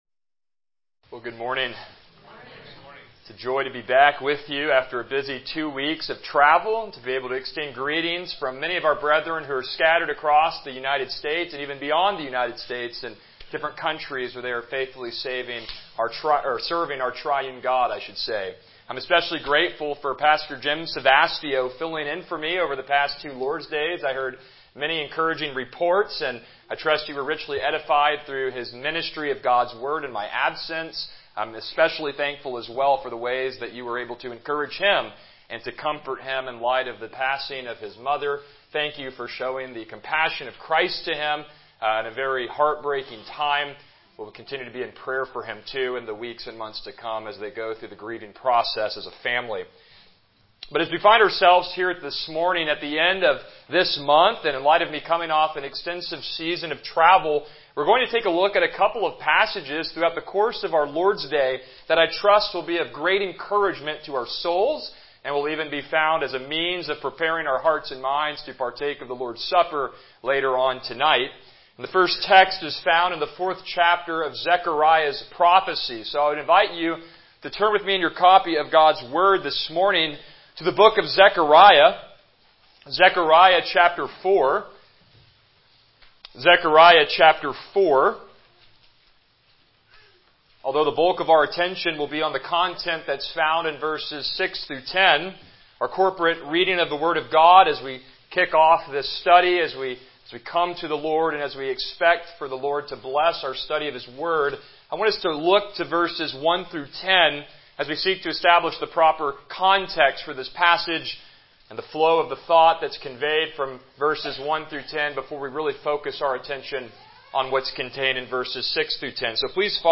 Passage: Zechariah 4:6-10 Service Type: Morning Worship